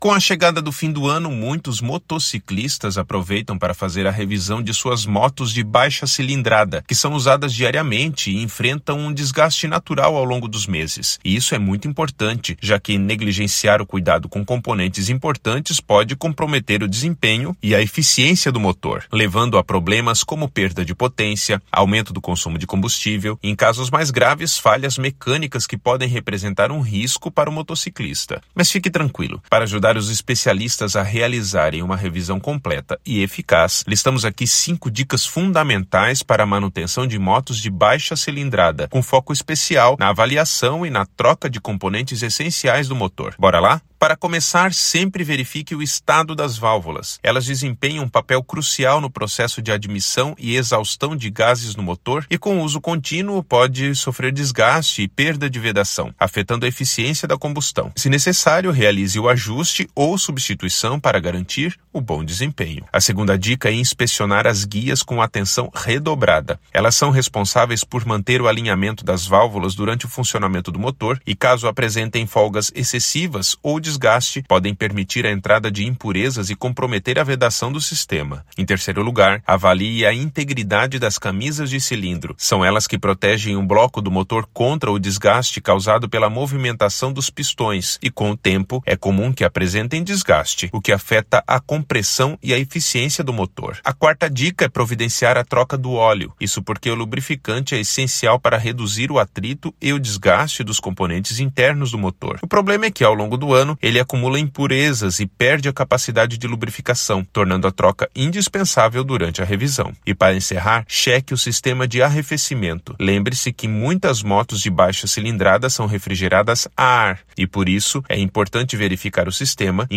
Narracao-04-motos-de-baixa-cilindrada.mp3